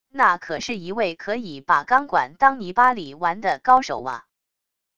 那可是一位可以把钢管当泥巴里玩的高手啊wav音频生成系统WAV Audio Player